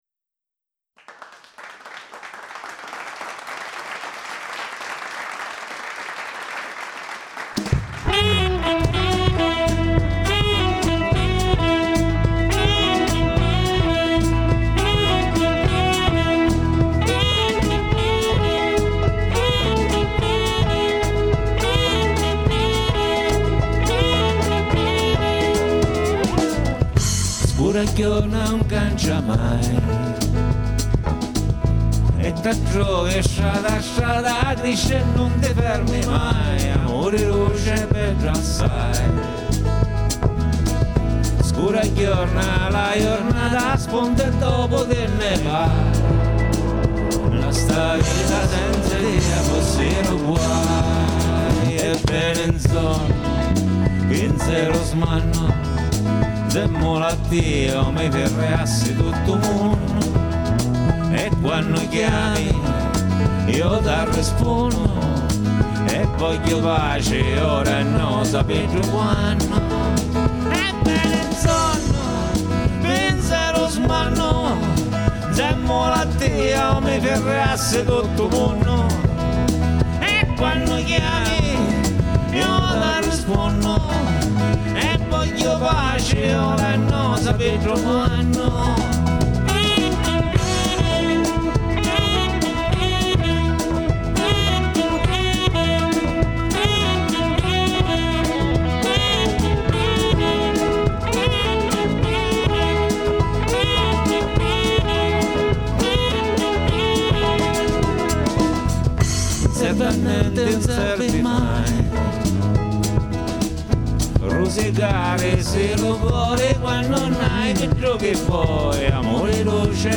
Buon ascolto - se volete ascoltarli così, grezzi e sporchi.